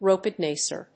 アクセント・音節rópe・dàncer